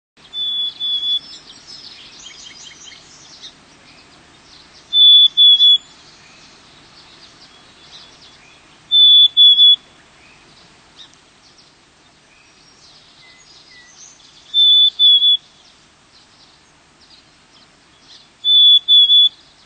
Black-Capped Chickadee
The more dee notes in a chickadee-dee-dee call, the higher the threat level.
Bird Sound
In most of North America, the song is a simple, pure 2 or 3-note whistled fee-bee or hey, sweetie. In the Pacific Northwest, the song is 3 or 4 notes on the same pitch; the song is also different on Martha's Vineyard in MA.
Black-cappedChickadee.mp3